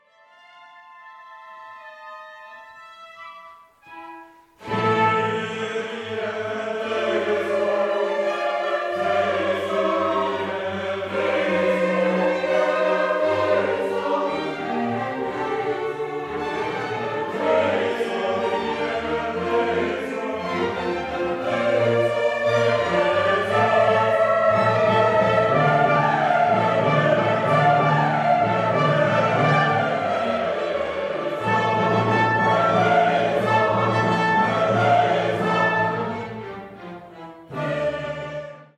The Needwood Singers are a choral society based in Barton Under Needwood, Staffs.